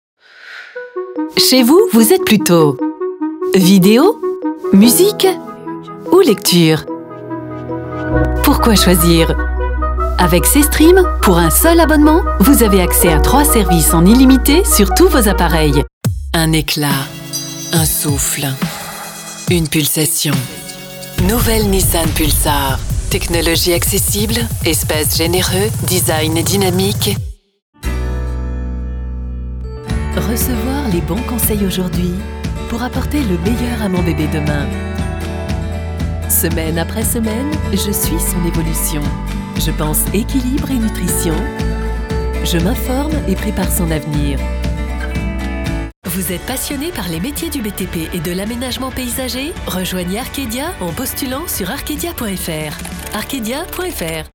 Démos pubs divers
Voix off